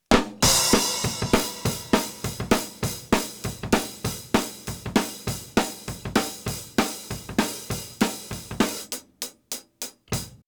Drums – Processed – Peak matched
Cuttertone_Peak.wav